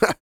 Index of /90_sSampleCDs/ILIO - Vocal Planet VOL-3 - Jazz & FX/Partition I/2 LAUGHS
A-LAUGH 1602.wav